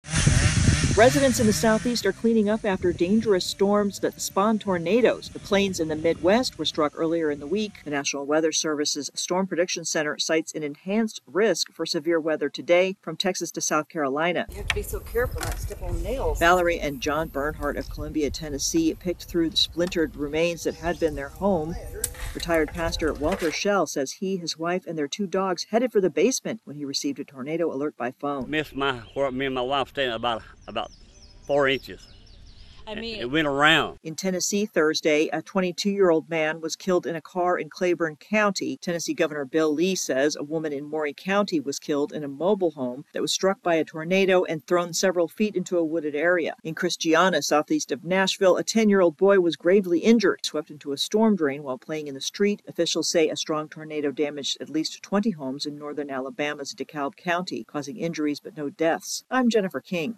((Begins with chainsaw sound))